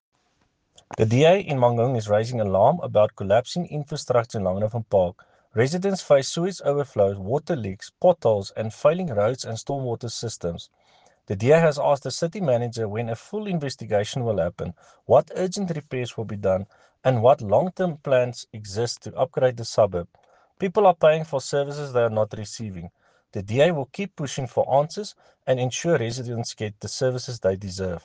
Afrikaans soundbites by Cllr Jan-Hendrik Cronje and